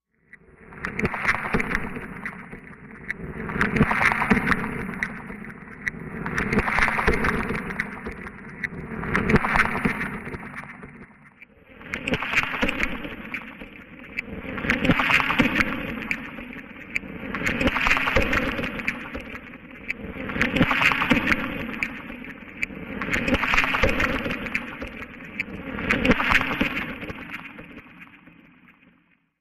Vapor Barrier, Machine, Metallic Flyby, Space Clang